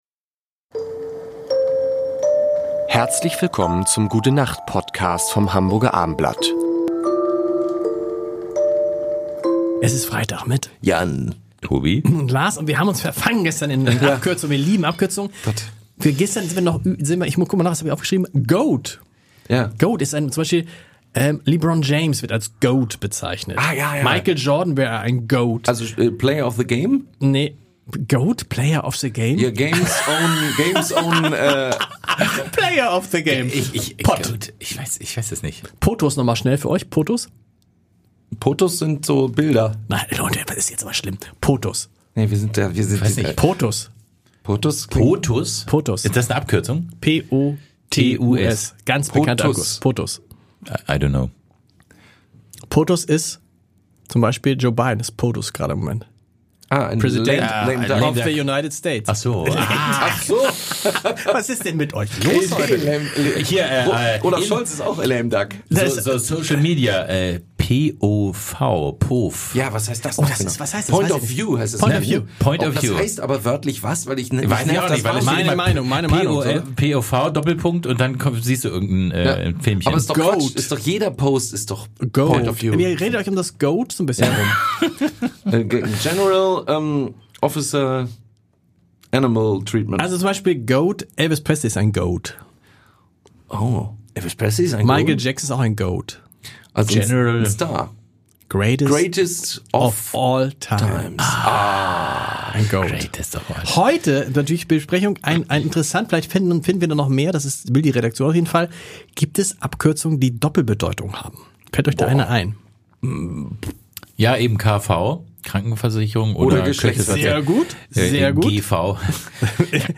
Sie erwarten unterhaltsame, nachdenkliche und natürlich sehr musikalische fünf Minuten.